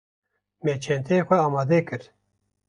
Pronounced as (IPA) /ɑːmɑːˈdɛ/